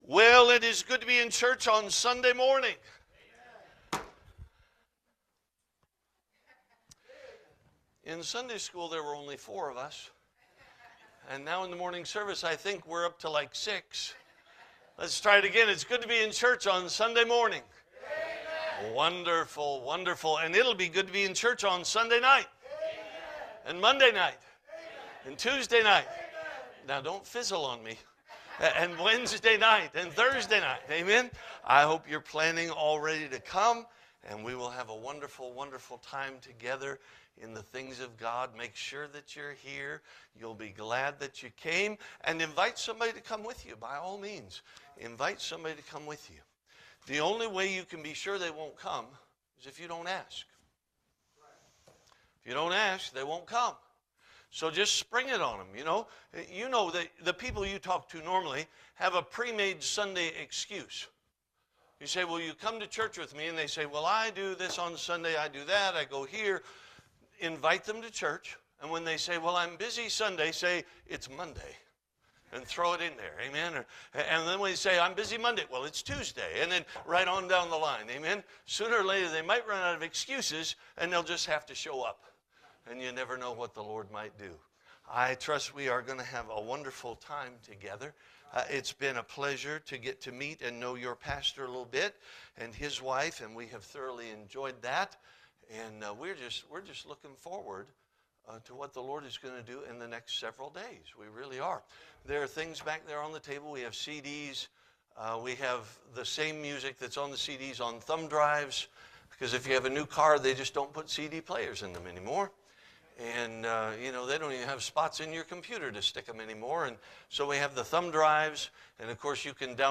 Spring Revival 2026 – Sunday Worship
Service Type: Revival